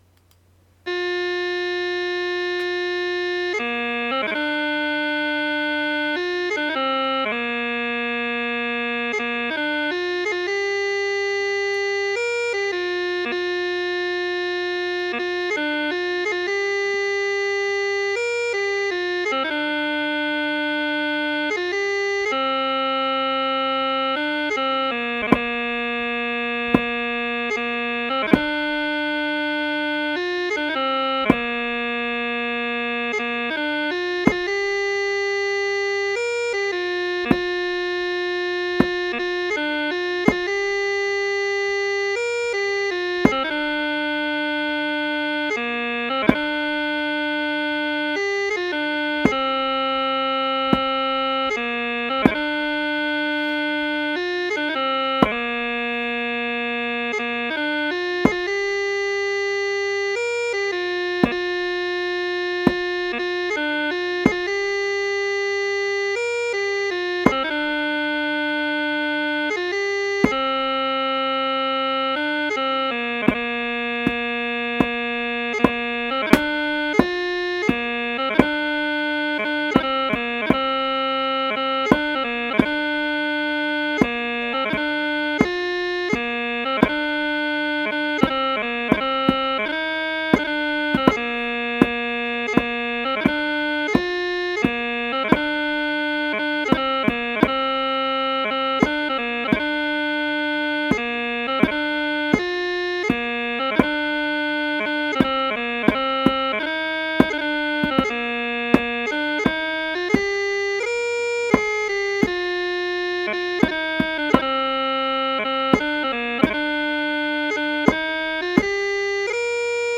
Tunes are based on a march tempo of 74bpm
Bass
DAMMJ1 TS v0b Chanter+Bass.mp3